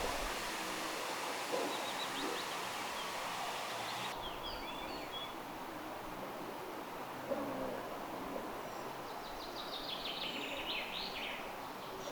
sepelkyyhky?
ehka_sepelkyyhkyn_erikoista_aantelya.mp3